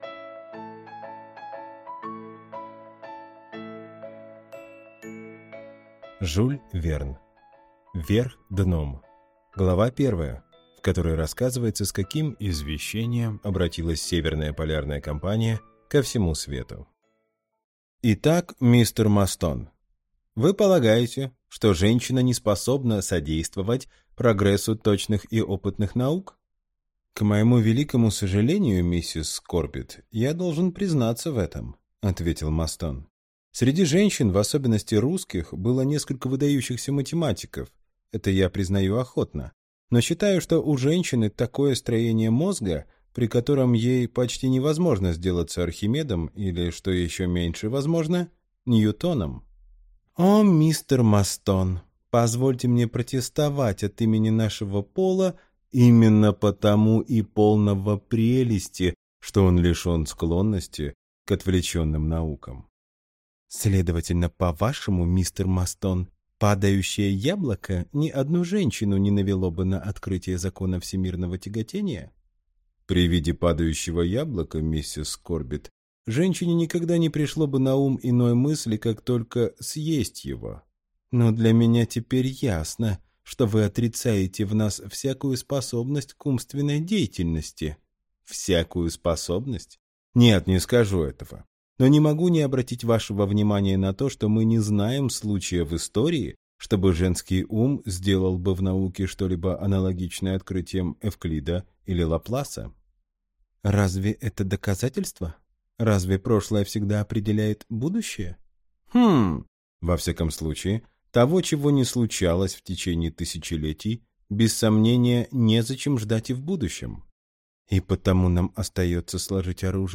Аудиокнига Вверх дном | Библиотека аудиокниг